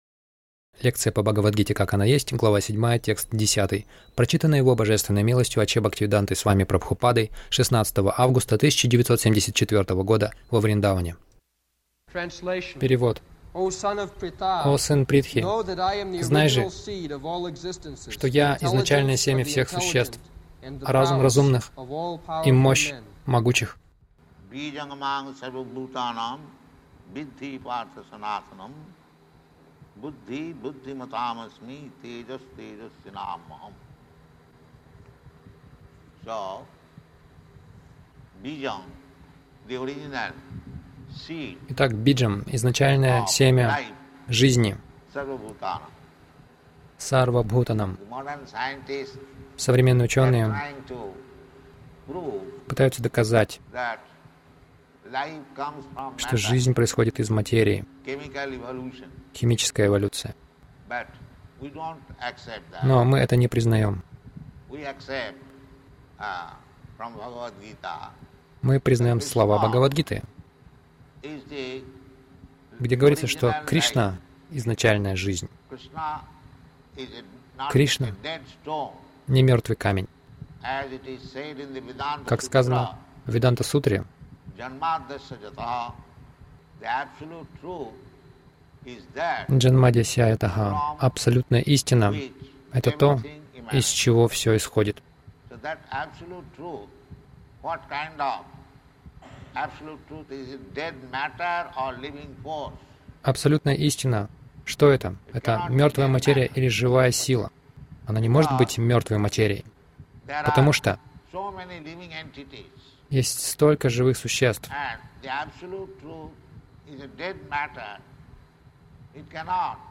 Милость Прабхупады Аудиолекции и книги 16.08.1974 Бхагавад Гита | Вриндаван БГ 07.10 — Санатана дхарма Загрузка...